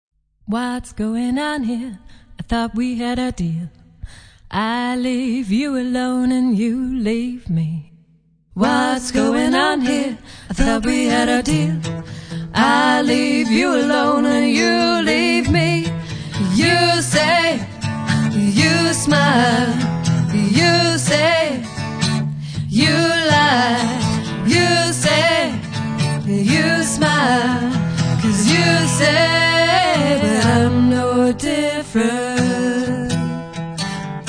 live blues music band